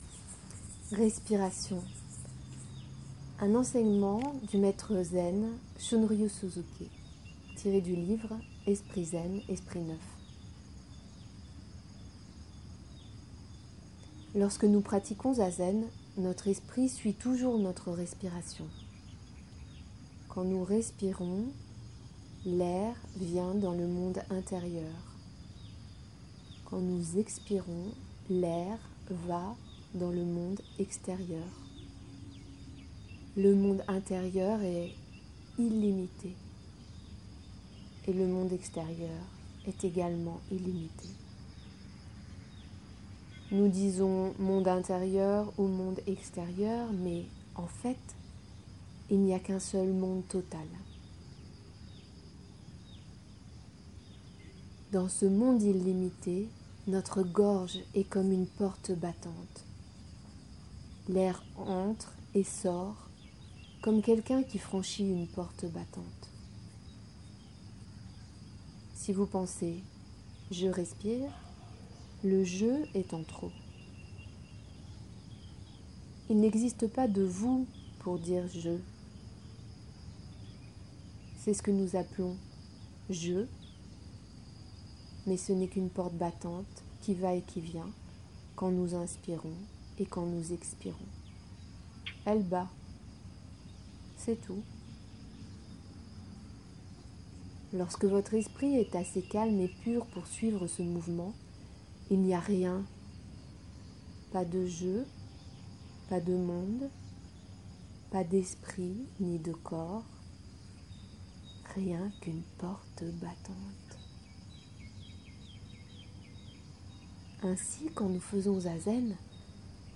Lecture d’un enseignement du maître bouddhiste zen Shunryu Suzuki.
Enregistré en Inde, à l’orée de la jungle, au petit jour.